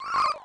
0F_Schrugging.mp3